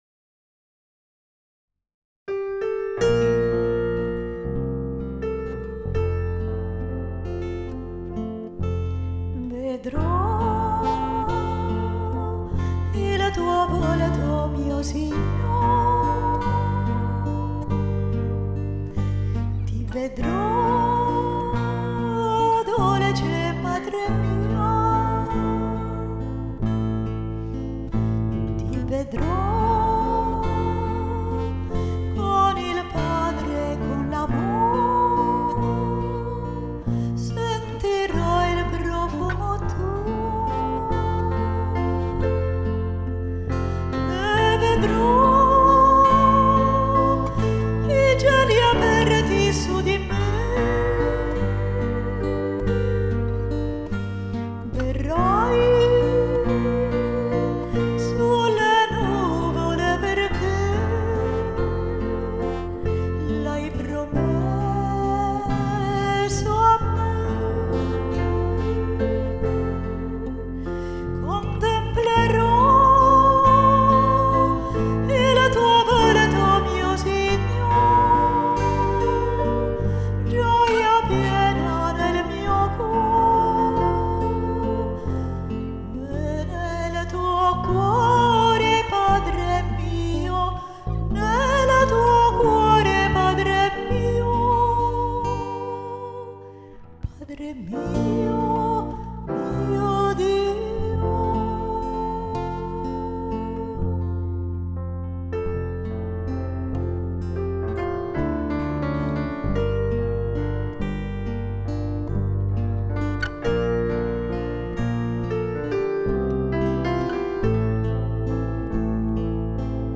Cieli aperti canto mp3